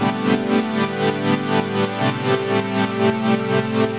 GnS_Pad-MiscA1:8_120-A.wav